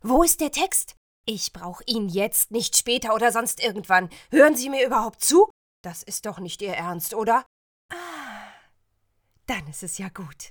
sehr variabel, hell, fein, zart, markant
Mittel minus (25-45)
Eigene Sprecherkabine
Audio Drama (Hörspiel), Comedy, Game, Lip-Sync (Synchron), Scene, Trick